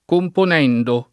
[ kompon $ ndo ]